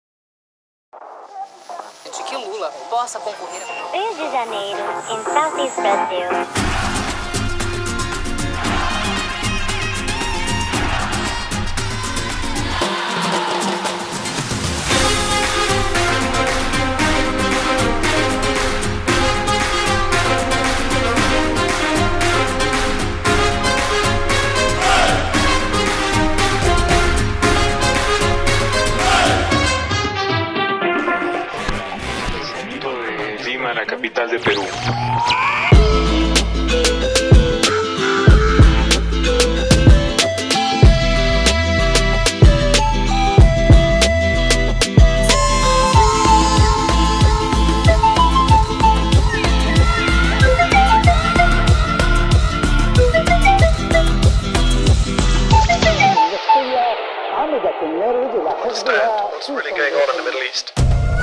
音色试听
电音采样包